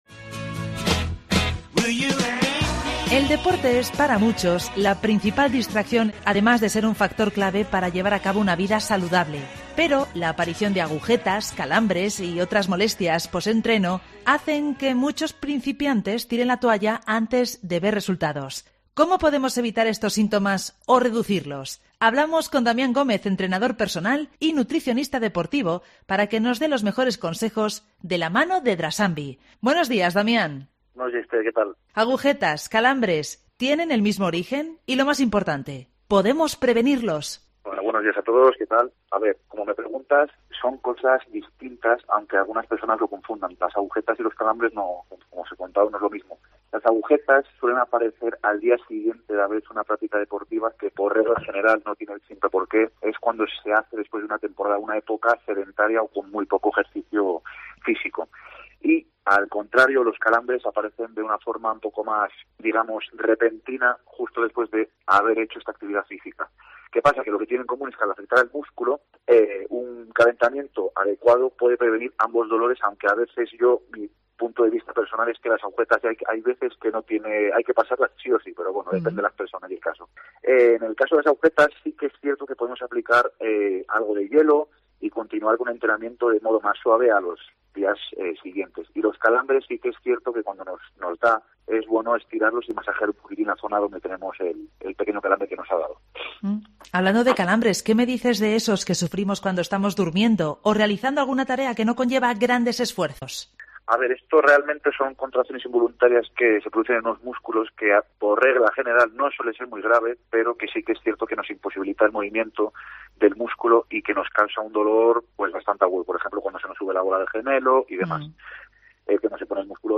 entrenador personal y nutricionista deportivo